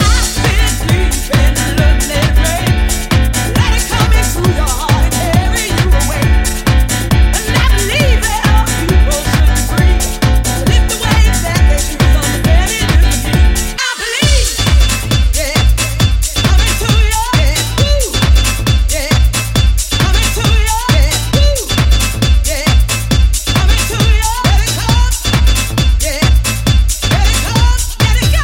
piano house classics
Genere: house, piano house, anni 90, successi, remix